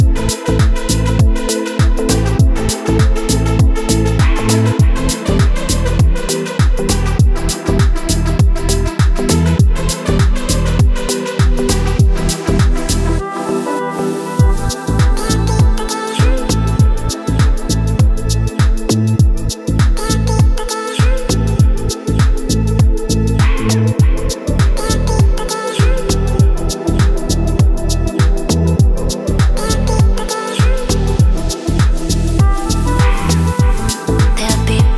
Жанр: Танцевальные / Электроника